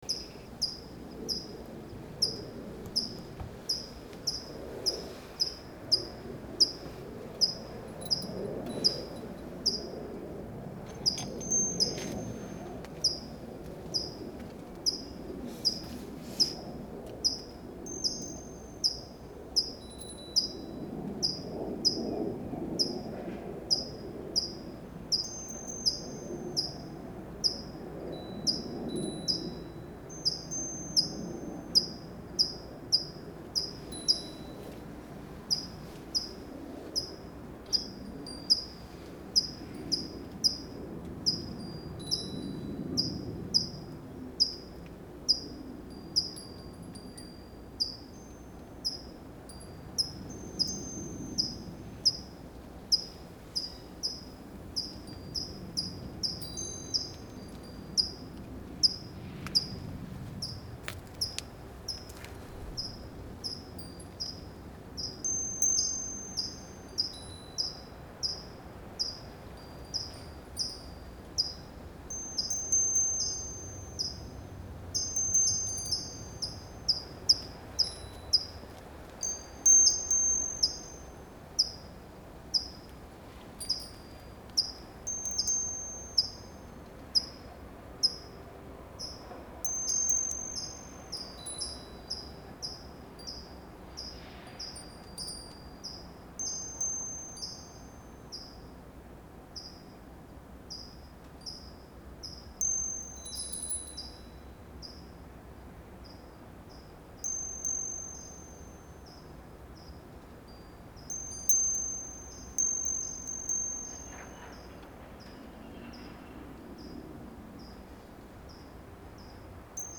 5/8 AM This Bird is Here Every Morning
LISTEN to one very persistent little bird.